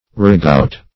Ragout \Ra*gout"\ (r[.a]*g[=oo]"), n. [F. rago[^u]t, fr.